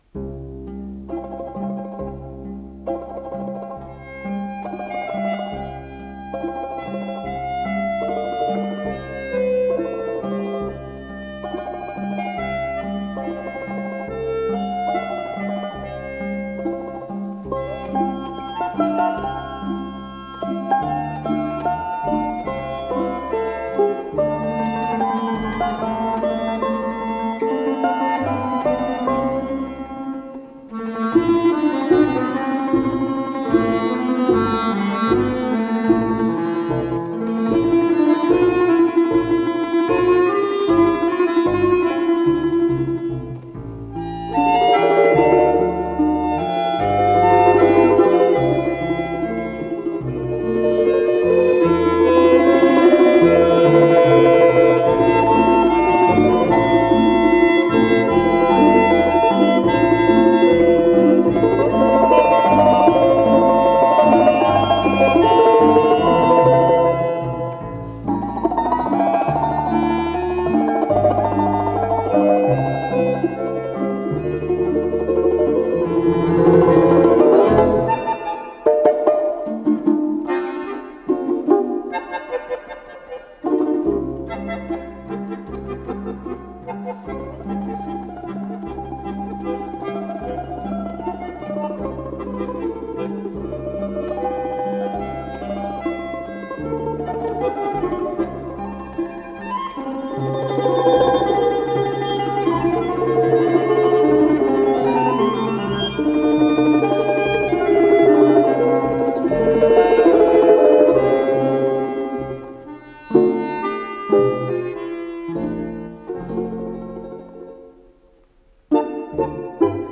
с профессиональной магнитной ленты
домра
баян
балалайка
балалайка-контрабас
ВариантДубль моно